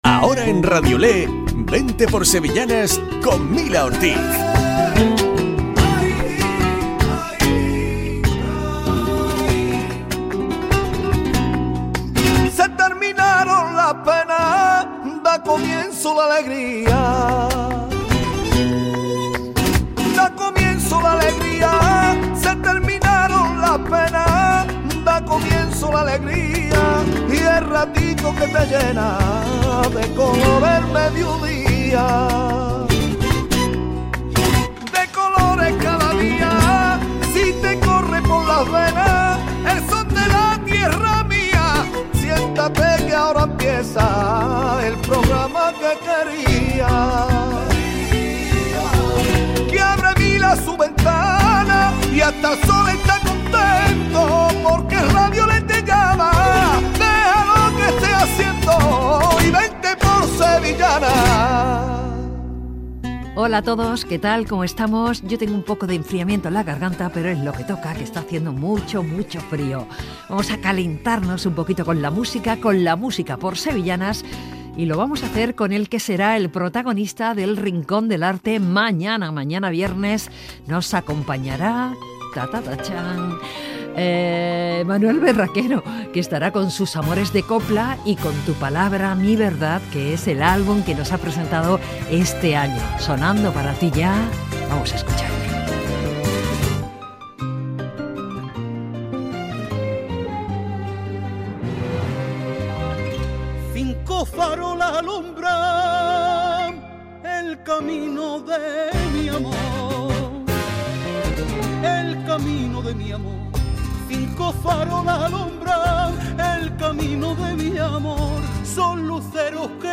Programa dedicado a las sevillanas .